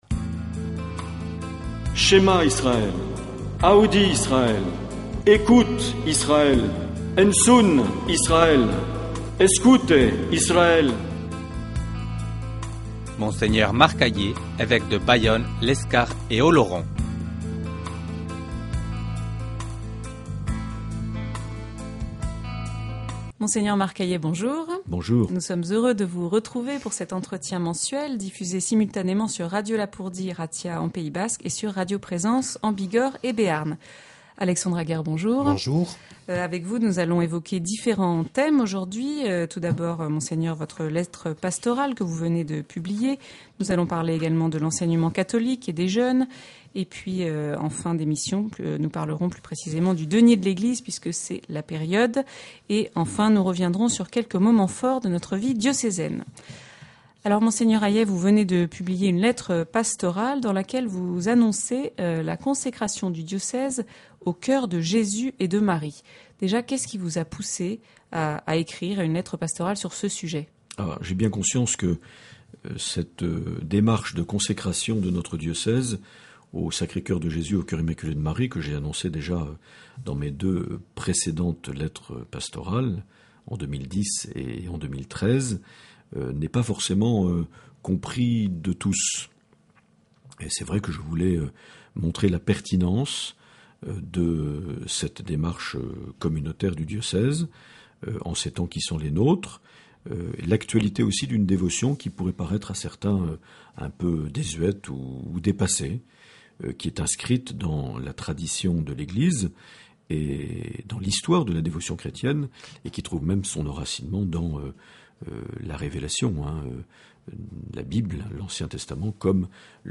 Une émission présentée par Monseigneur Marc Aillet